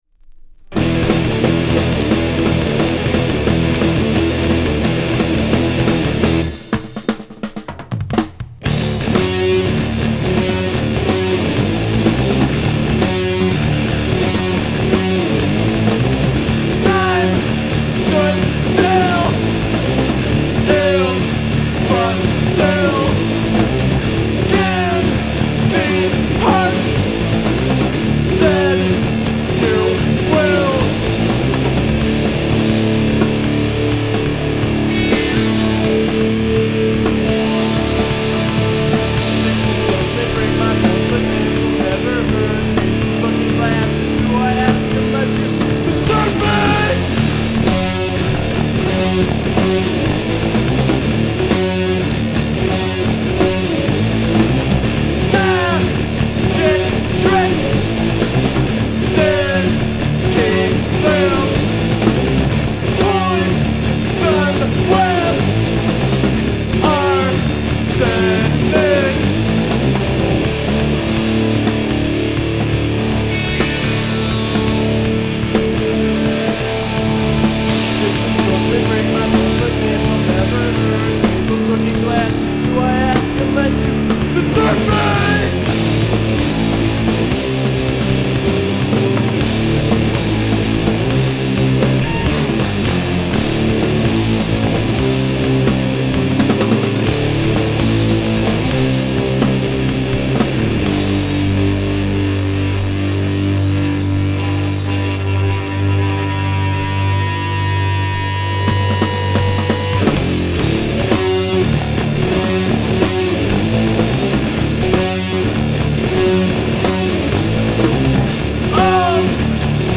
Drums/Vocals
Guitar/Vocals
Bass/Vocals
Emo , Indie